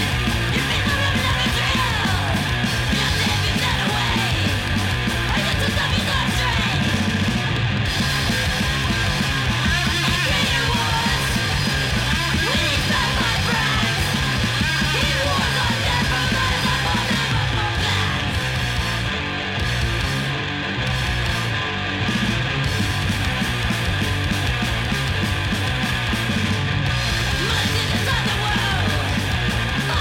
até irromperem os riffs downtuned, thrashy
alternados entre solos curtos e d-beats implacáveis
principalmente dentro da lógica do crust punk